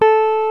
FLYING V 1.wav